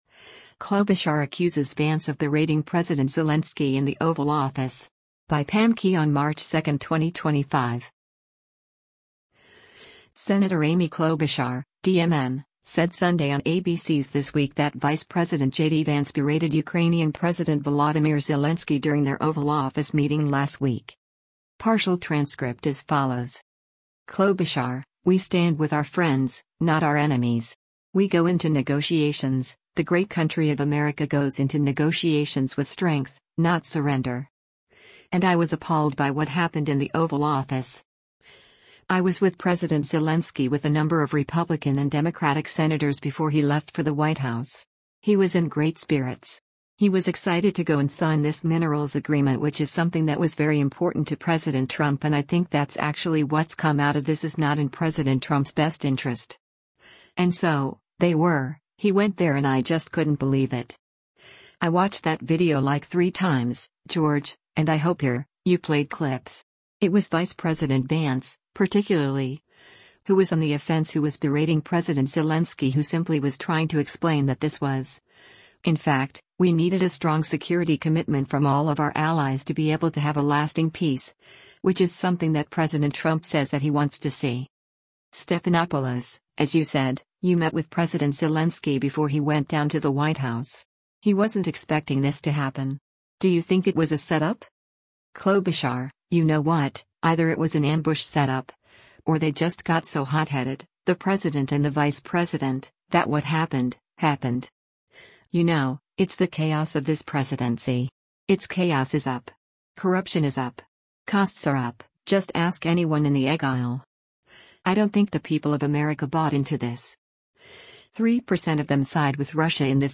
Senator Amy Klobuchar (D-MN) said Sunday on ABC’s “This Week” that Vice President JD Vance berated Ukrainian President Volodymyr Zelensky during their Oval Office meeting last week.